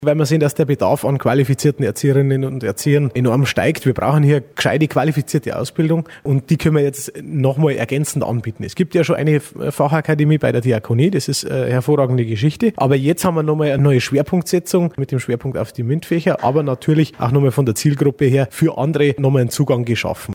Für die Region ist die Einrichtung enorm wichtig, so Walch: